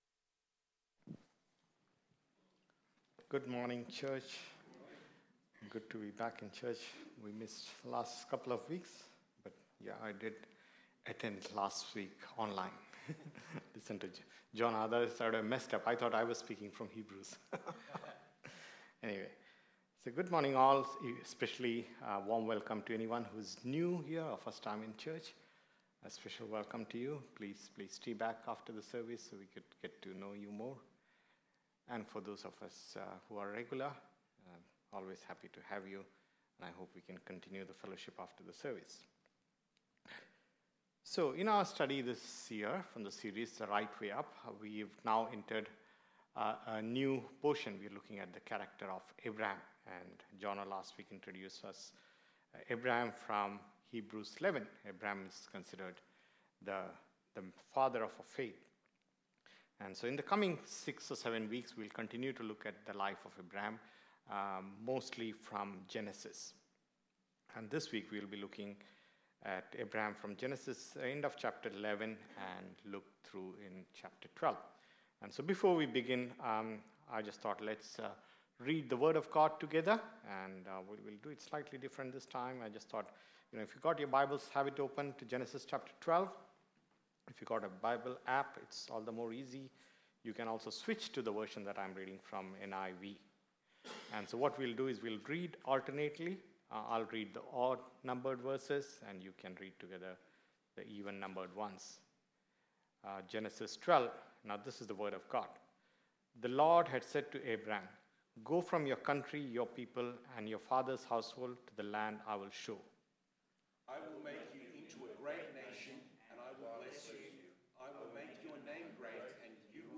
KPCC Sermons | Kings Park Community Church